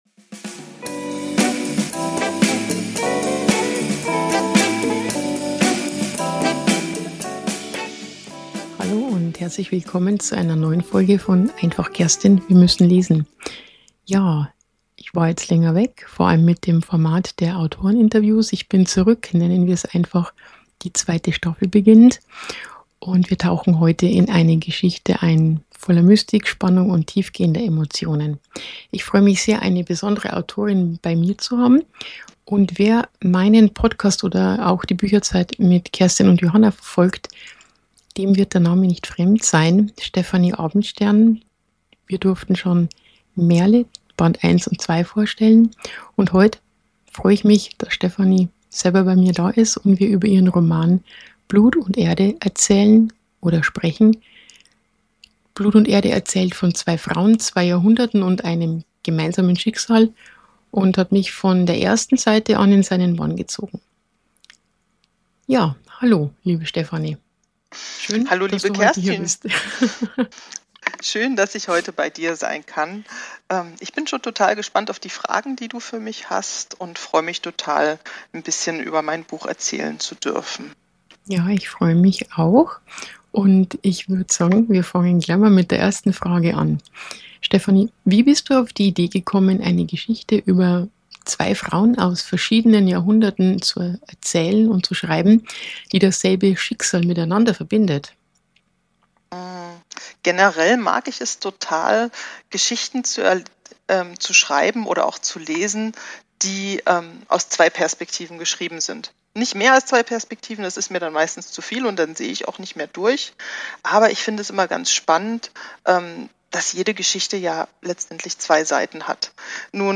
Autroeninterview